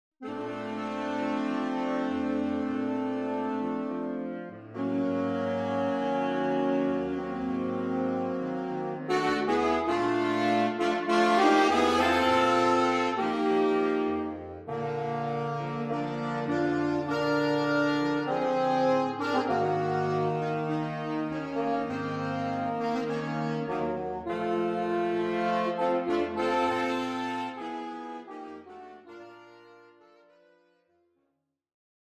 (Sultry rumba)
Soprano Saxophone
Alto Saxophone
Tenor Saxophone
Baritone Saxophone